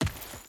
Dirt Chain Run 3.wav